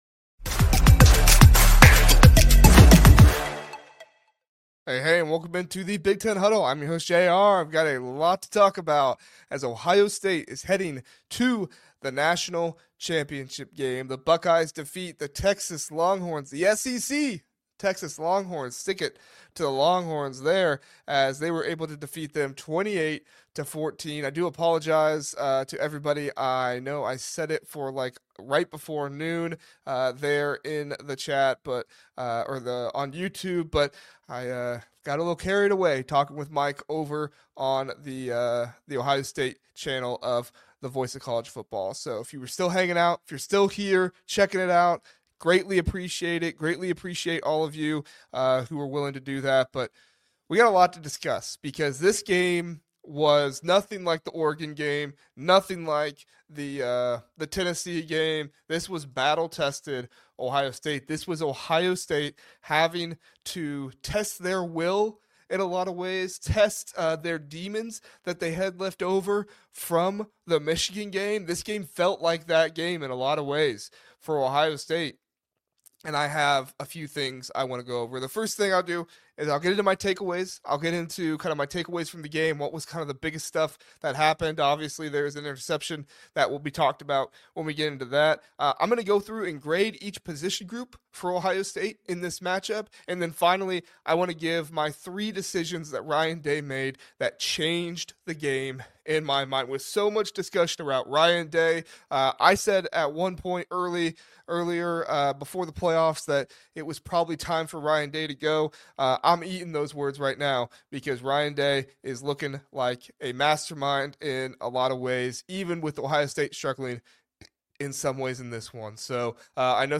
The Big Ten Huddle covers EVERY Big Ten football and basketball team by rotating in all of our partner podcast hosts to touch on viewpoints from all over the Big Ten.